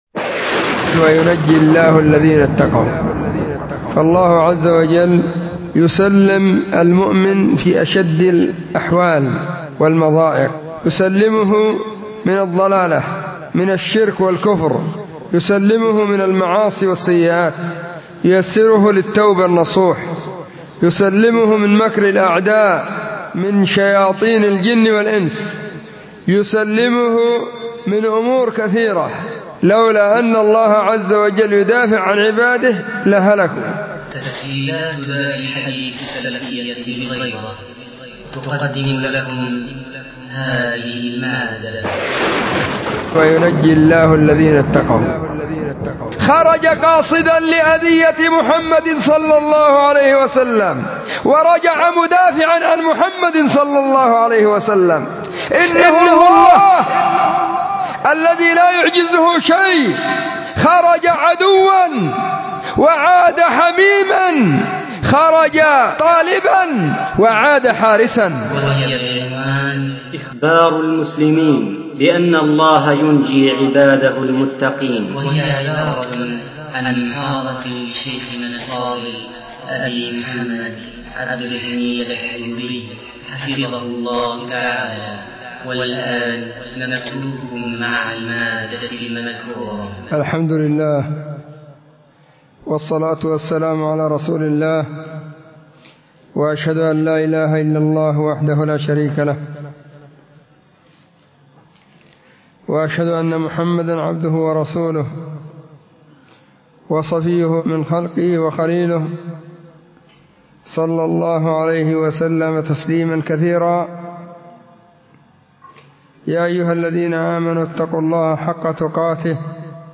محاضرة بعنوان *إخبار المسلمين بأن الله ينجي عباده المتقين*
📢 مسجد الصحابة – بالغيضة – المهرة، اليمن حرسها الله،